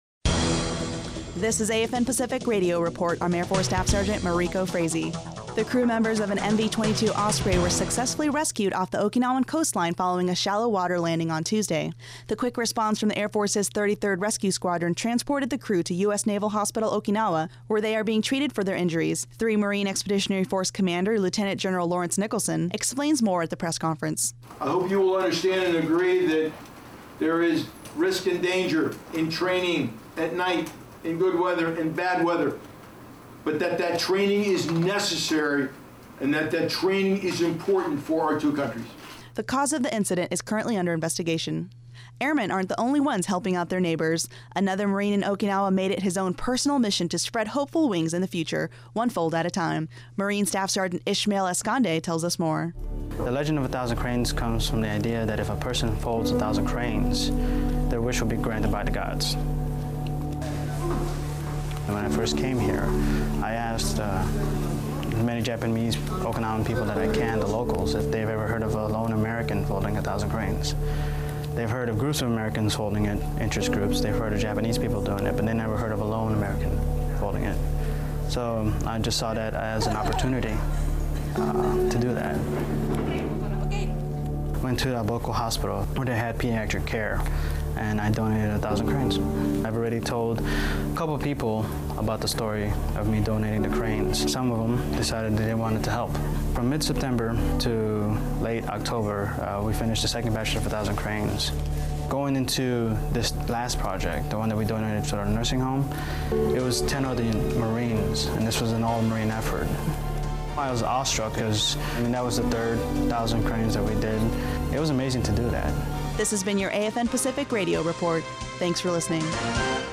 In this Pacific Radio Report, the crewmembers of an Okinawa-based MV-22 were successfully rescued following a shallow water landing on Tuesday. III Marine Expeditionary Force Commander Lieutenant General Lawrence Nicholson explains the importance of training regarding the incident during a press conference held on Wednesday.